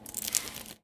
R - Foley 114.wav